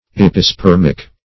Search Result for " epispermic" : The Collaborative International Dictionary of English v.0.48: Epispermic \Ep`i*sper"mic\, a. (Bot.)